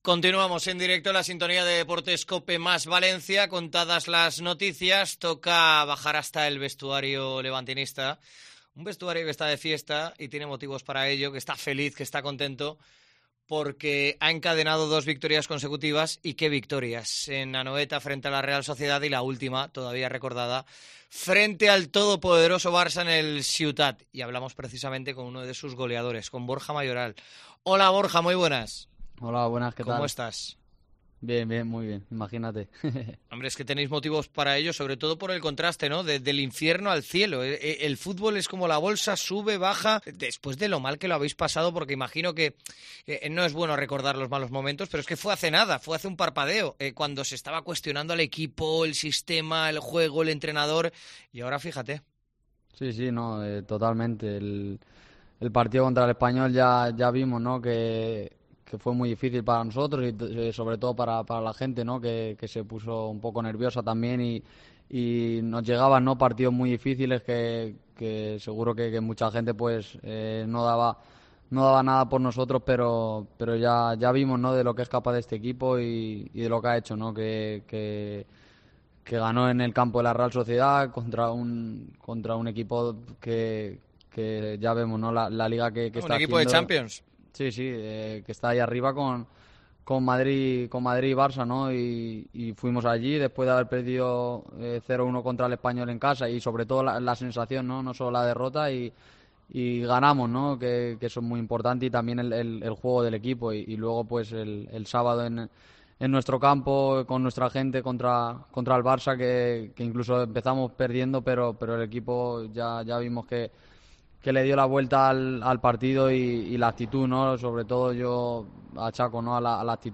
El delantero atiende a DEPORTES COPE VALENCIA y defiende la continuidad del entrenador y el cambio del equipo
ENTREVISTA A BORJA MAYORAL